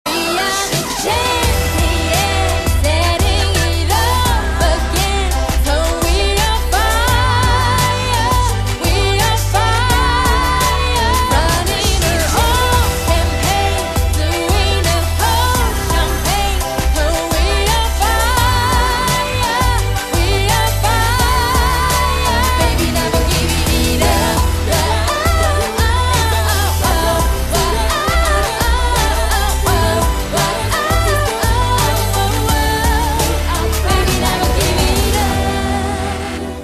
M4R铃声, MP3铃声, 欧美歌曲 107 首发日期：2018-05-13 16:22 星期日